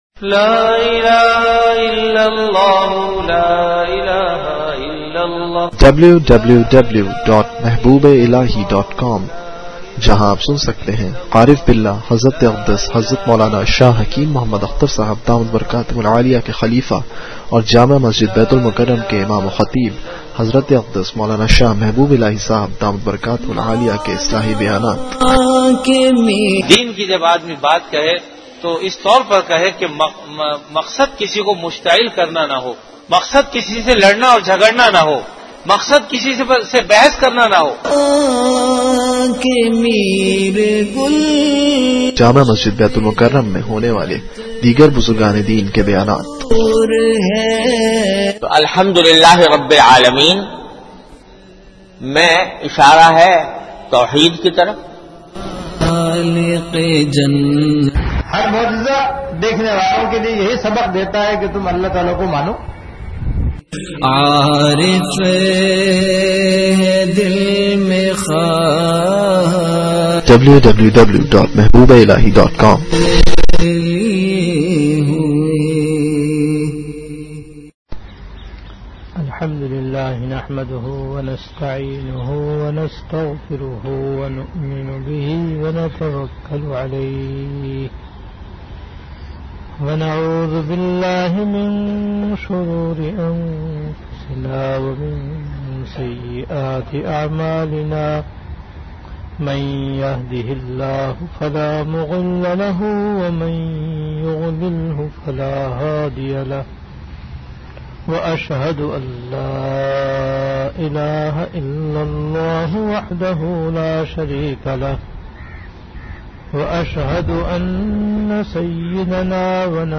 Delivered at Jamia Masjid Bait-ul-Mukkaram, Karachi.
Bayanat · Jamia Masjid Bait-ul-Mukkaram
After Asar Prayer